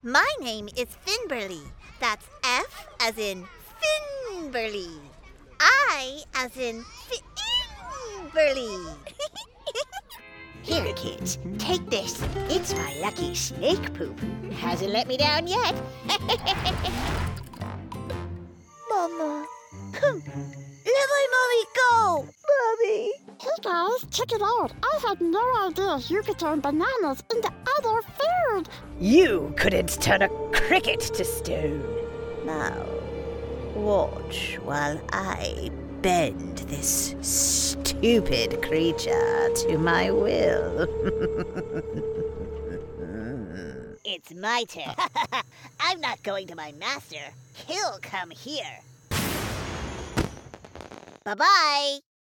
Animation
American neutral; American southern; American Minnesota; British (RP); British (Cockney); French (Parisian); Latina; Canadian